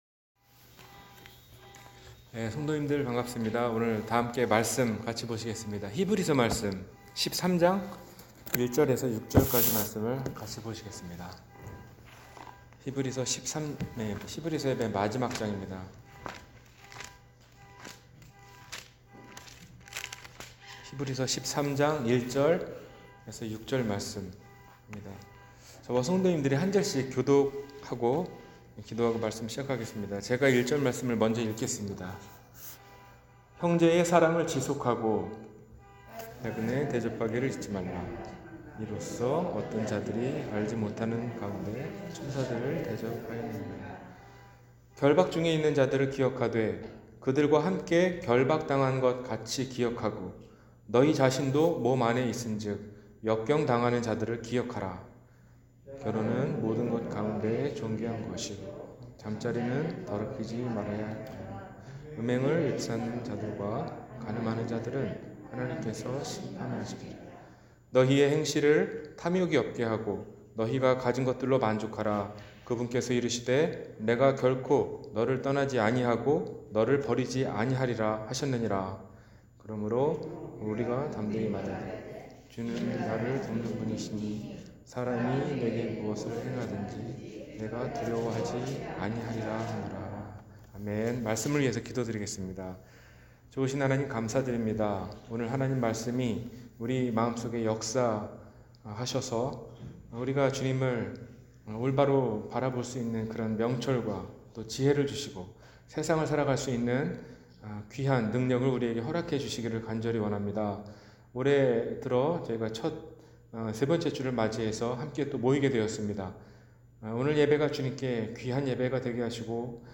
마지막 권면 – 주일설교